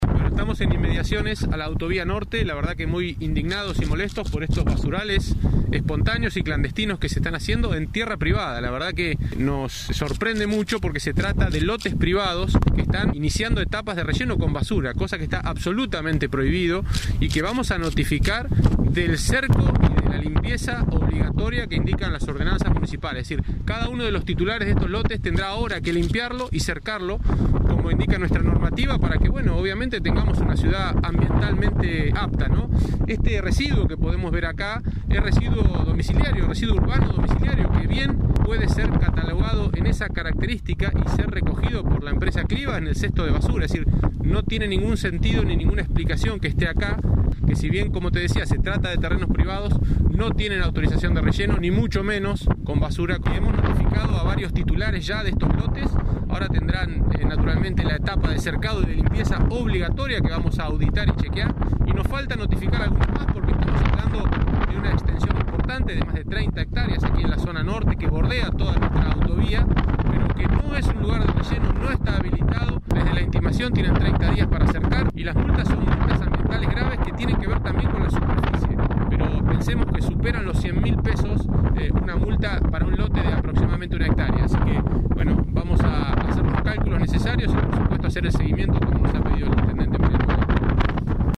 Francisco Baggio, subsecretario de Medioambiente y Protección Ciudadana.